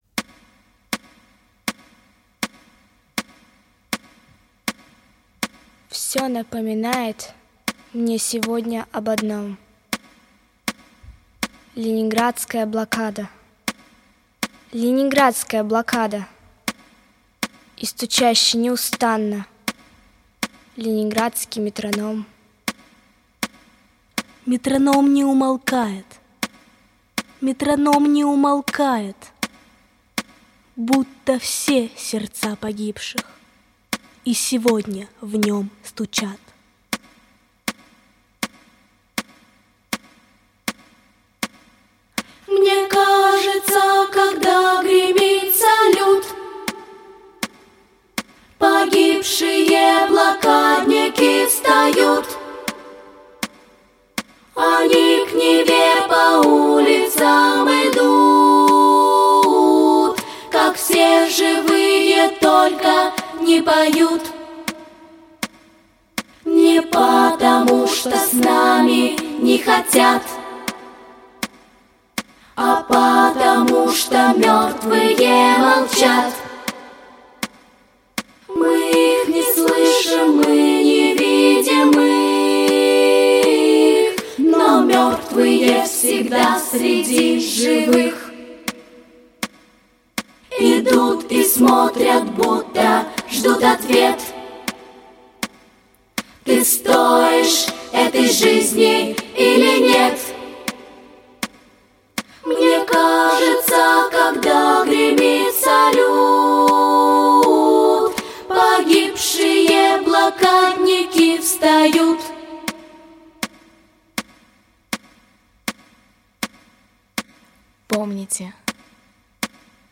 • Категория: Детские песни
акапелла